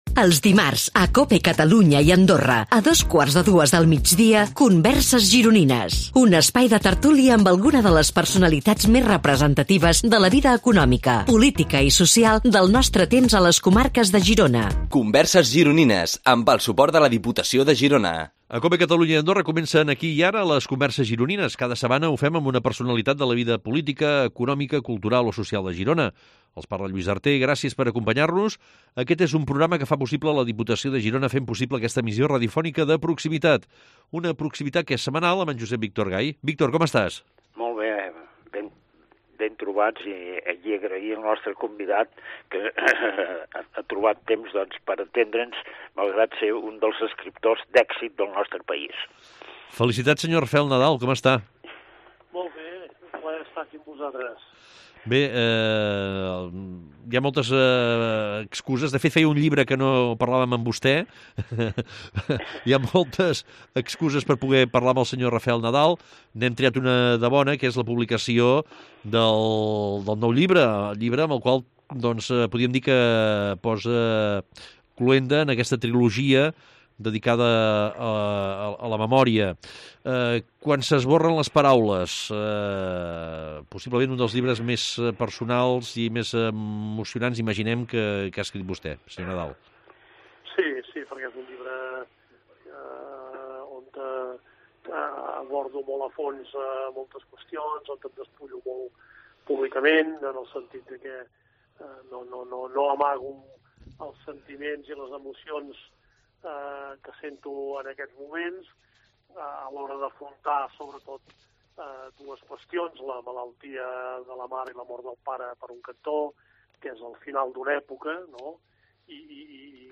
Aquestes converses es creen en un format de tertúlia en el que en un clima distès i relaxat els convidats ens sorprenen pels seus coneixements i pel relat de les seves trajectòries. Actualment el programa s’enregistra i emet en els estudis de la Cadena Cope a Girona, situats en el carrer de la Sèquia número tres de Girona, just al costat del museu del Cinema.